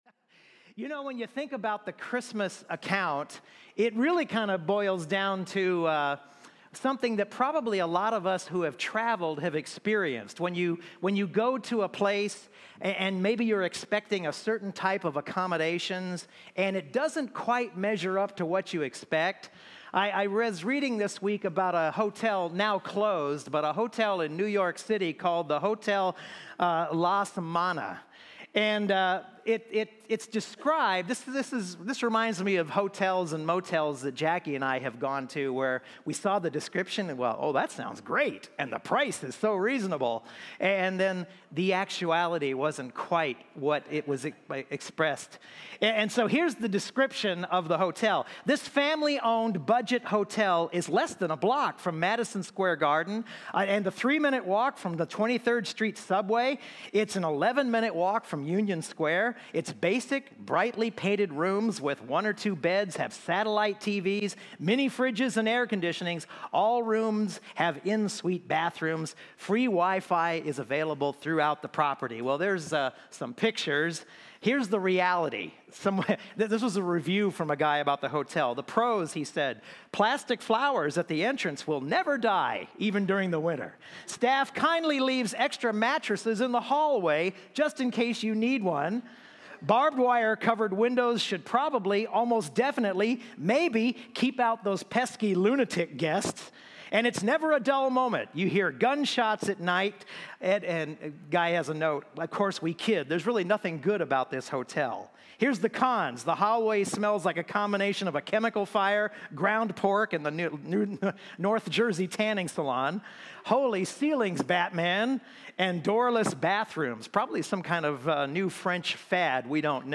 Christmas Eve Candlelight Sermon